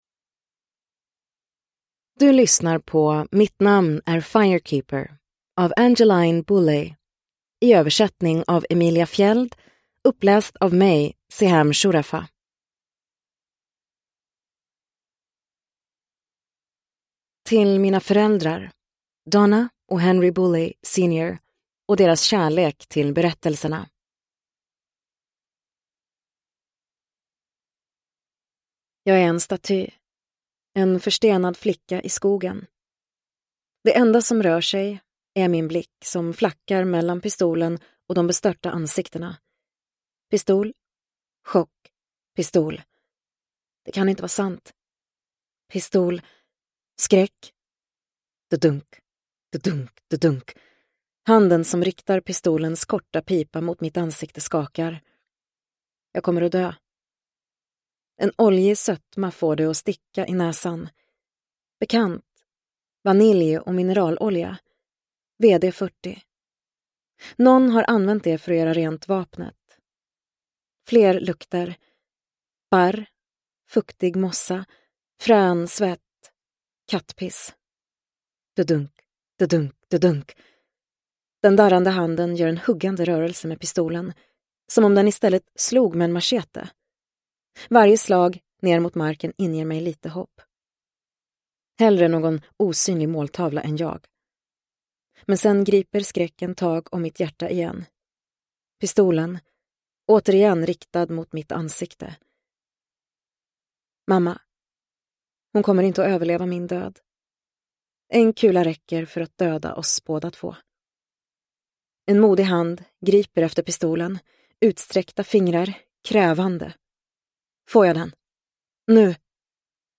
Mitt namn är Firekeeper – Ljudbok – Laddas ner